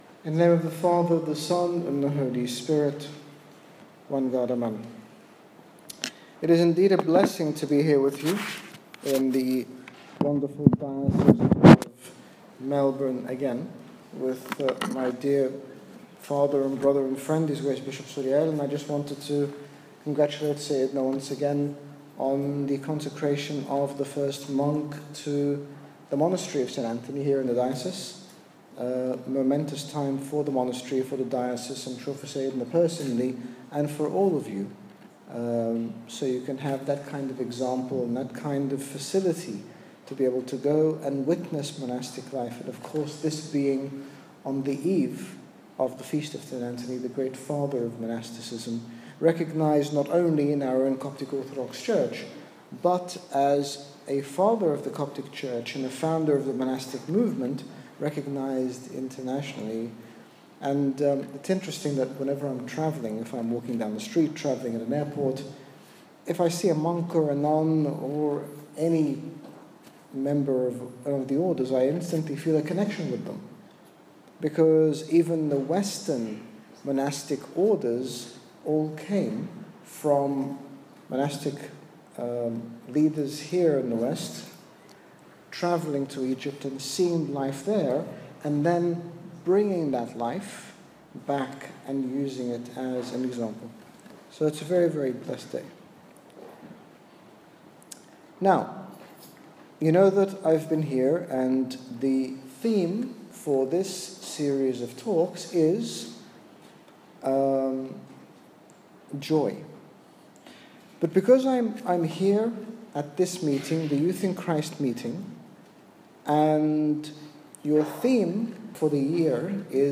In the third part of this series on JOY, His Grace Bishop Angaelos, General Bishop of the Coptic Orthodox Church in the United Kingdom, speaks to us about living joyfully in Christ, the things that hinder us from that joy, and the ways in which we can return to a life of joy if we have strayed. Download Audio Read more about Living joyfully in Christ - JOY Series P3 Australia - HG Bishop Angaelos